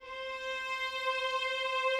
strings_060.wav